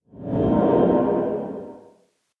cave12.mp3